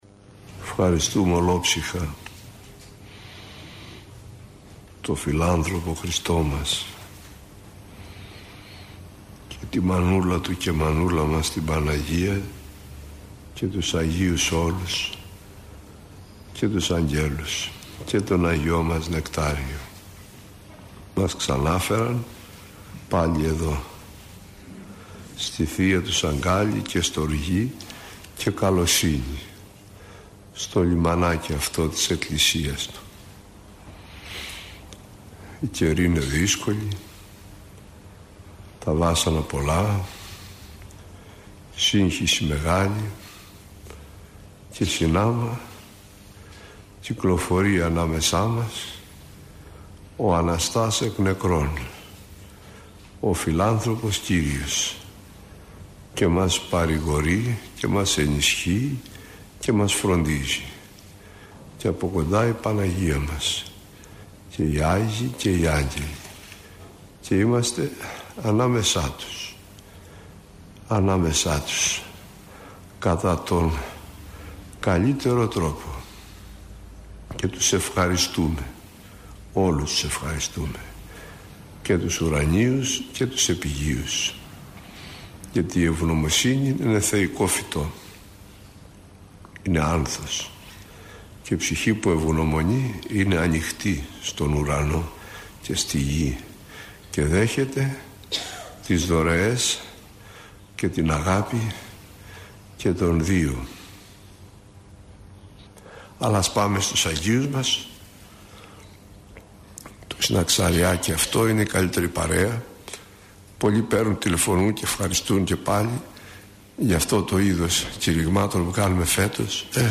Άγιοι που εορτάζουν από 11 έως 17 Οκτωβρίου – ηχογραφημένη ομιλία
Η εν λόγω ομιλία αναμεταδόθηκε από τον ραδιοσταθμό της Πειραϊκής Εκκλησίας.